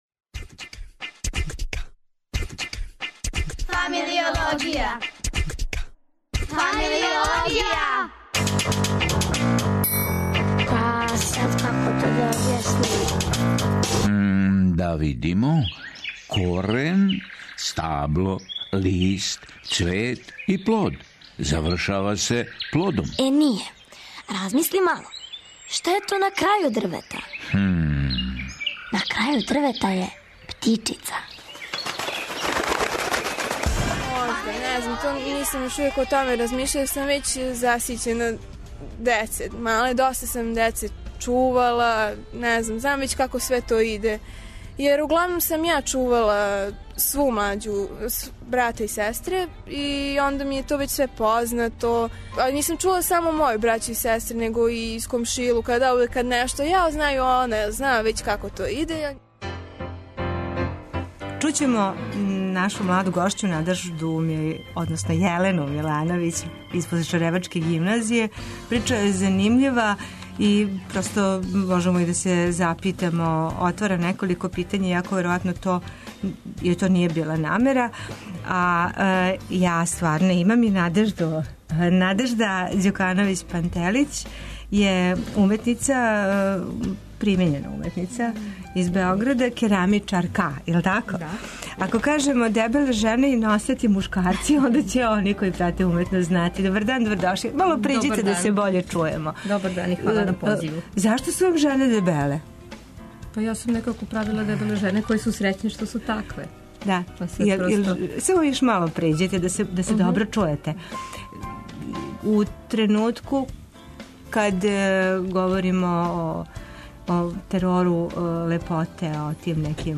Радио Београд 1, 13.05